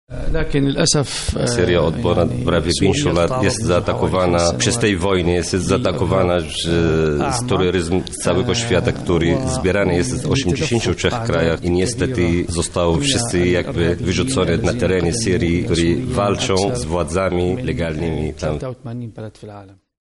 Naturalnym miejscem dla wszystkich Syryjczyków jest Syria – mówił ambasador Syrii, Idis Mayya.
Ambasador Syrii odwiedził wczoraj Lublin w ramach debaty „Polityka imigracyjna: za czy przeciw”.